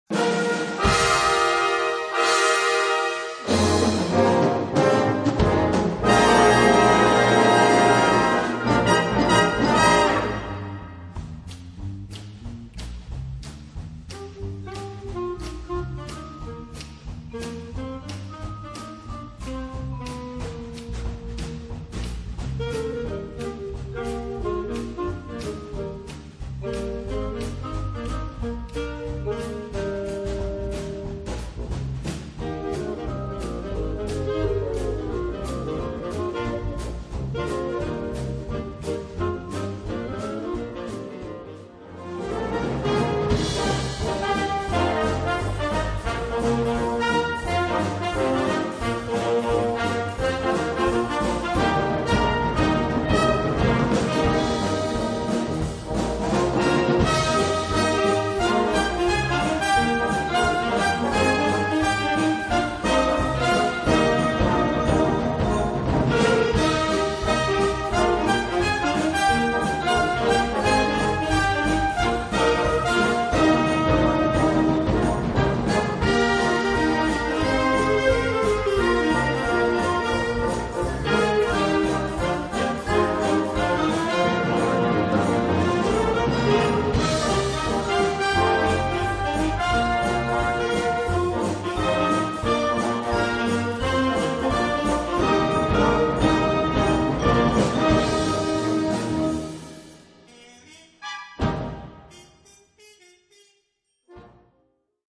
Gattung: Swing
Besetzung: Blasorchester